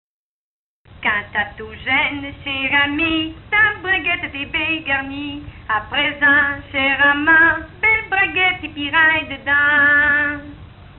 Divertissements d'adultes - Couplets à danser
danse : branle : courante, maraîchine
Pièce musicale inédite